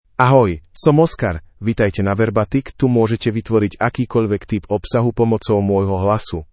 OscarMale Slovak AI voice
Oscar is a male AI voice for Slovak (Slovakia).
Voice sample
Male
Oscar delivers clear pronunciation with authentic Slovakia Slovak intonation, making your content sound professionally produced.